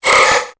Cri de Tarsal dans Pokémon Épée et Bouclier.